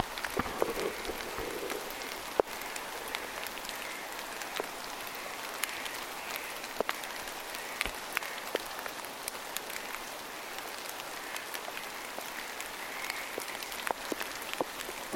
luontopolulle kuului heikosti kauempaa pähkinähakin ääntelyä
kauempaa_kuuluu_pahkinahakki_ehkapa_nuori_koska_aani_poikkeaa_tavallisesta.mp3